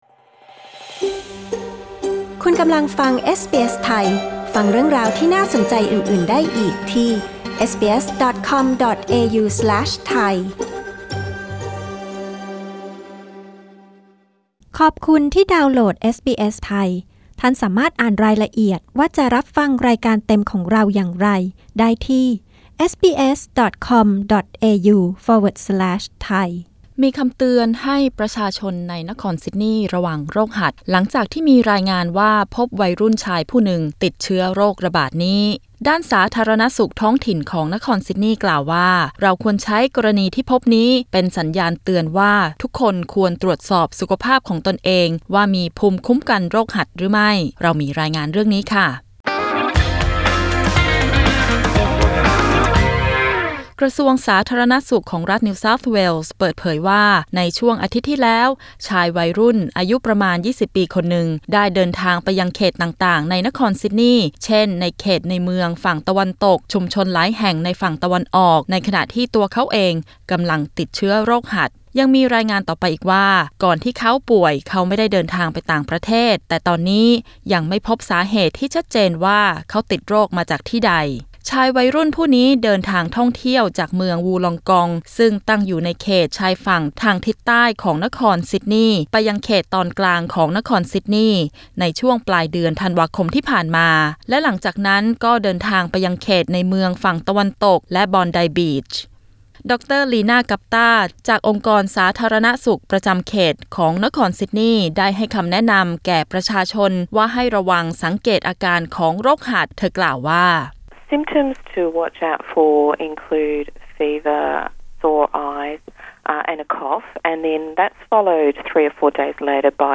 NEWS: สาธารณสุขนครซิดนีย์เตือนประชาชนให้ระวังโรคหัดระบาด ล่าสุดพบผู้ติดเชื้อเพิ่ม 5 ราย พร้อมเตือนทุกคนควรตรวจสอบภูมิคุ้มกันโรคหัดของตน